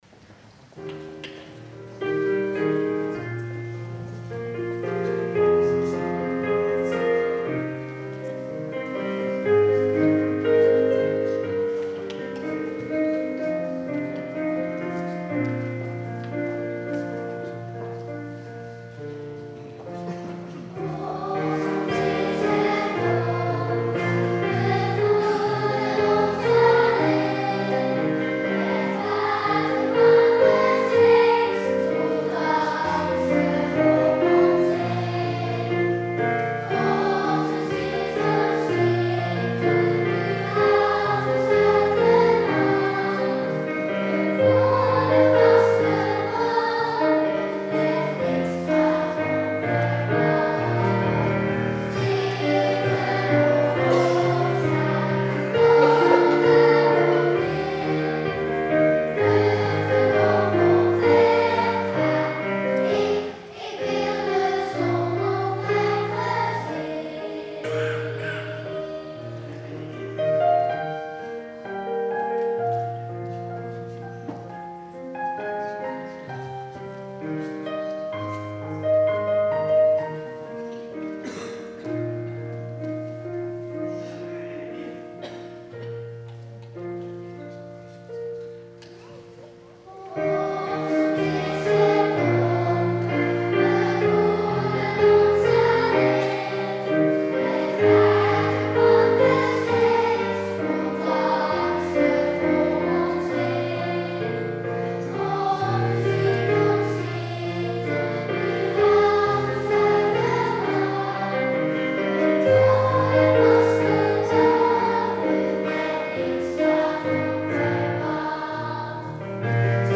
viering met kinderkoor en gemengd koor
Lied onder de communie kinderkoor
15 - kinderkoor onbekend.mp3